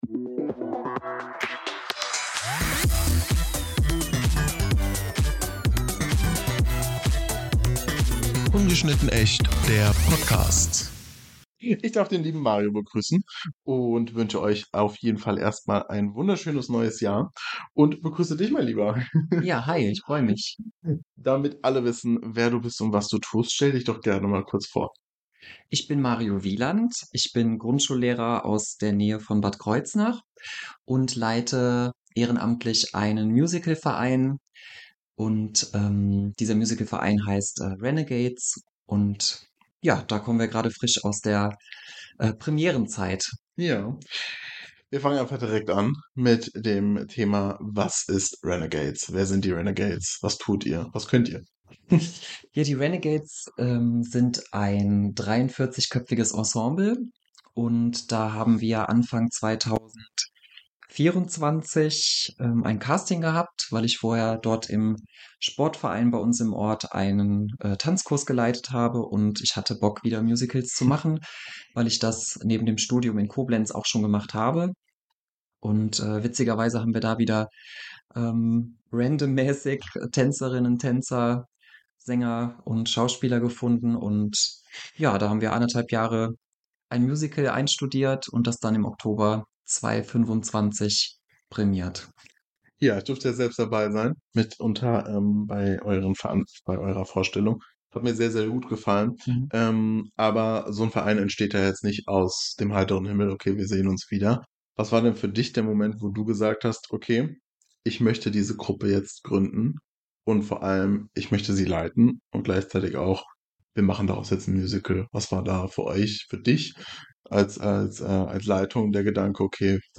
Es geht um Mut, Sichtbarkeit, Zusammenhalt und um Kunst als Ort der Heilung. Ein starkes Gespräch zum Jahresbeginn.